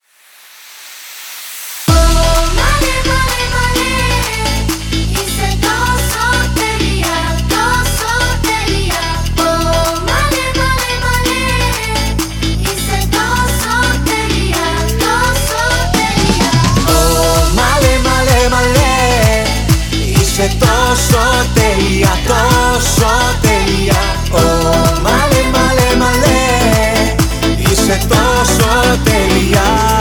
поп
мужской вокал
женский вокал
восточные мотивы
дуэт